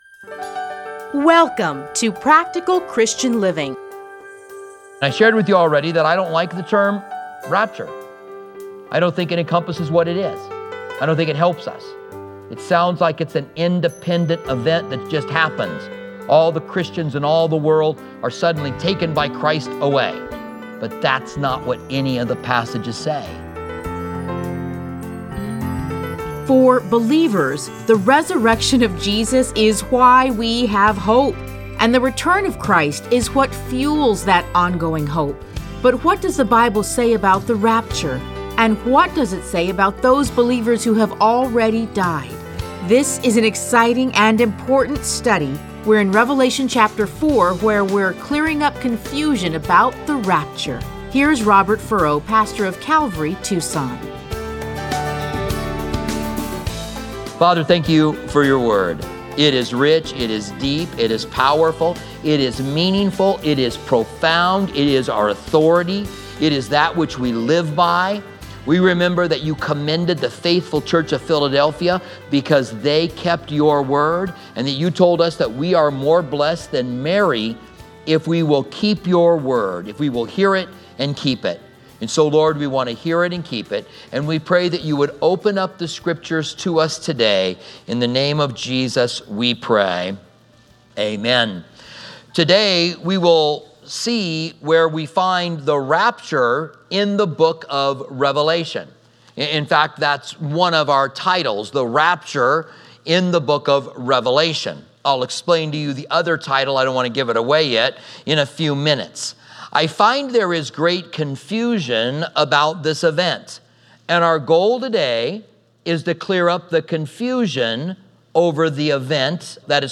Listen to a teaching from Revelation 4:1-3.